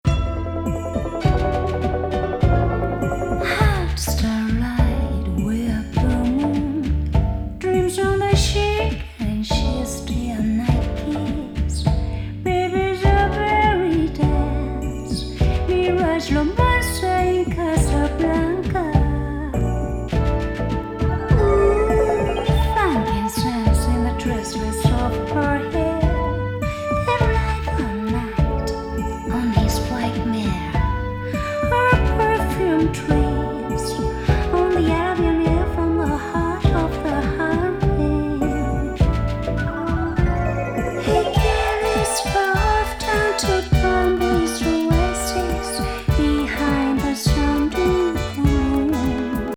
和スペーシー・エキゾ・グルーヴ名作!!